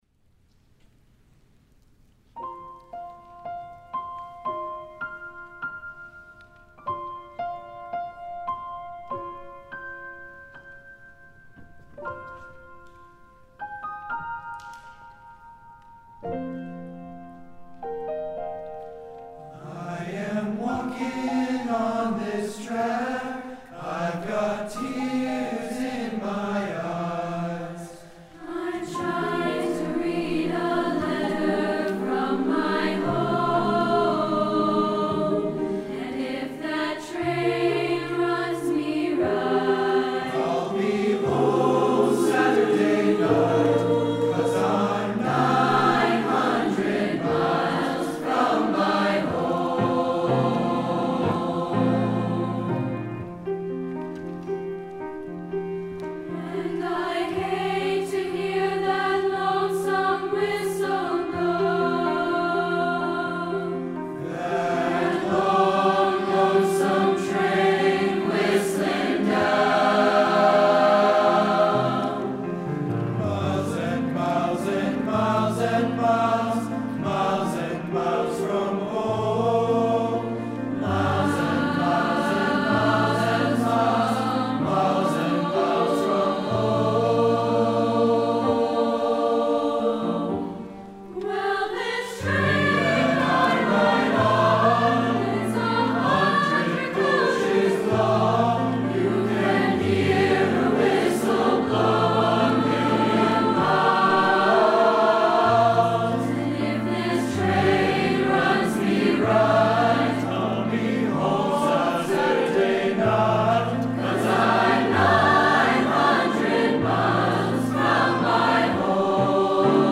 2018 Charter Oak Music Festival
Mixed Choir